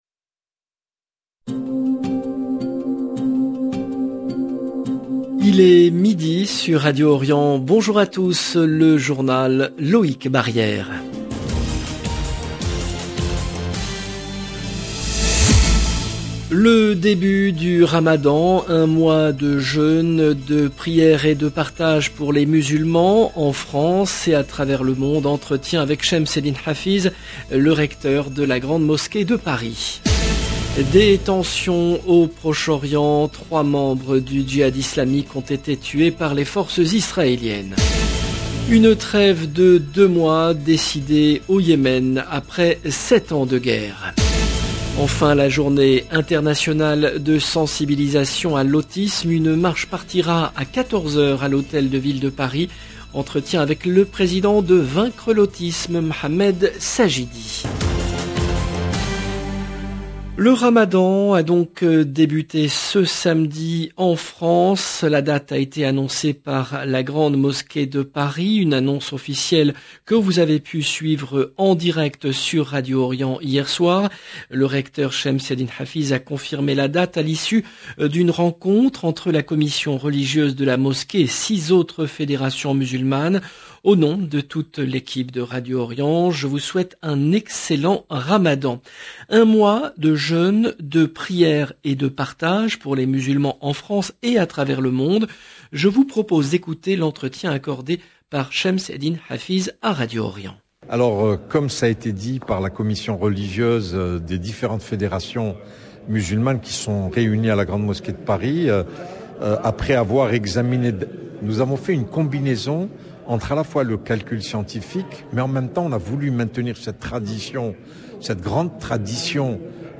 Entretien avec Chems Eddine Hafiz, le recteur de la Grande Mosquée de Paris.